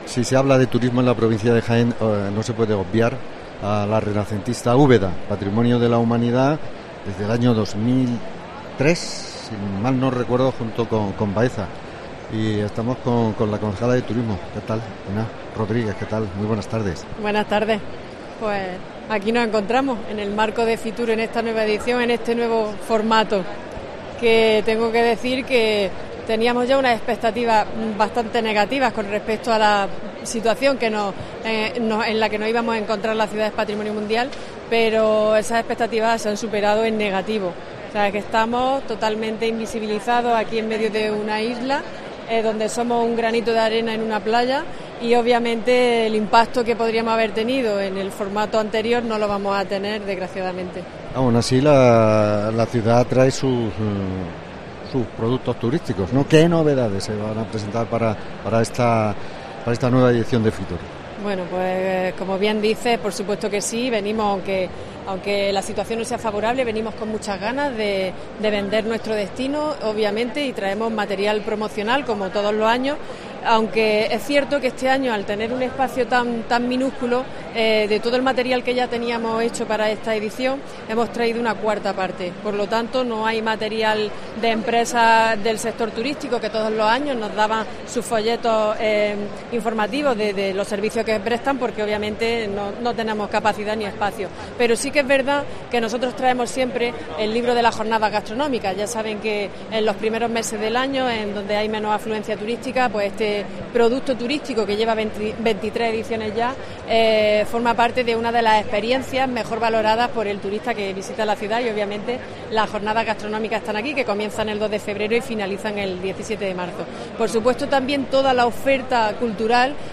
Hablamos en FITUR con la concejal de Turismo de Úbeda